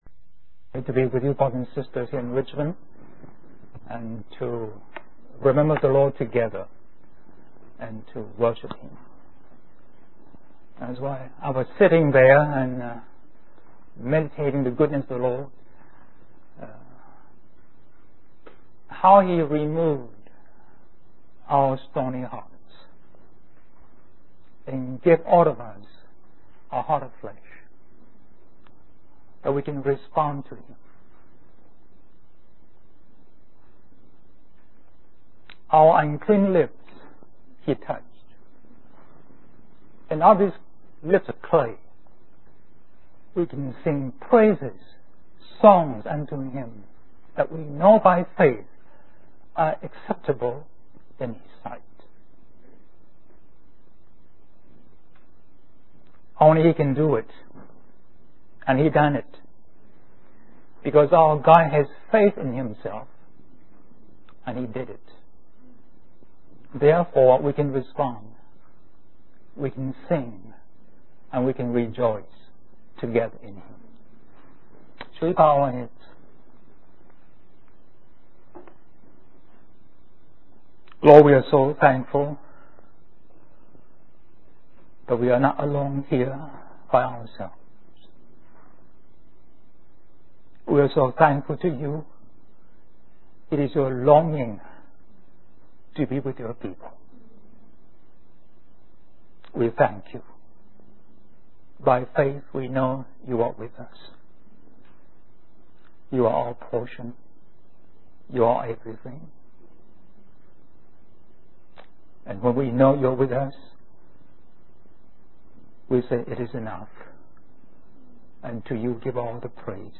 In this sermon, the preacher emphasizes the importance of faith in our lives. He mentions that faith brings us rest, joy, peace, stability, worship, and courage. The preacher refers to the story of Abraham as the father of faith and highlights the importance of believing in the impossible.